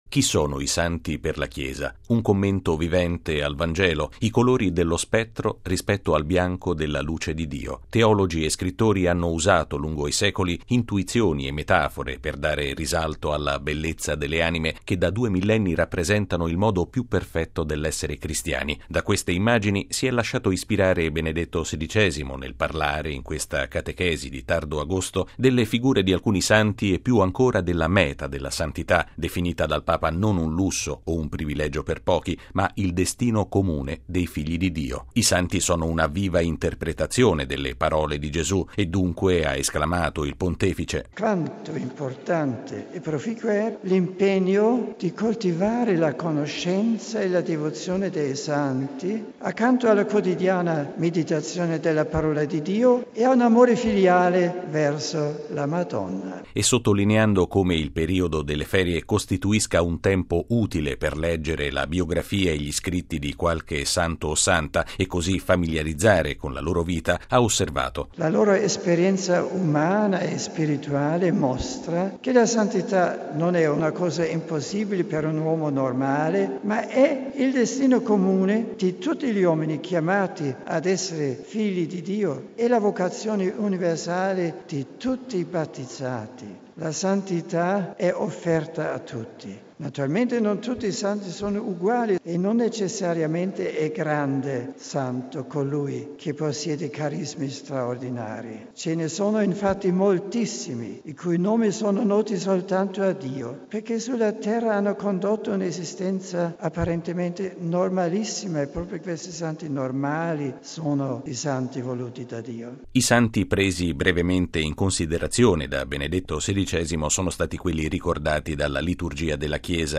E’ l’invito che questa mattina Benedetto XVI ha rivolto ai circa 4 mila fedeli che hanno affollato il cortile del Palazzo apostolico di Castel Gandolfo per prendere parte all’udienza generale. Il Papa ha espresso, fra l’altro, solidarietà alla nazione polacca, colpita nei giorni scorsi da una grave ondata di maltempo che ha causato molte vittime e danni.
Benedetto XVI ha concluso l’udienza generale con i saluti in sei lingue.
(applausi)